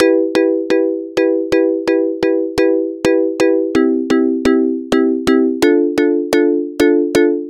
马林巴舞厅的旋律
描述：幸福的马林巴舞曲，128BPM！"。
Tag: 128 bpm House Loops Percussion Loops 1.26 MB wav Key : Unknown Reason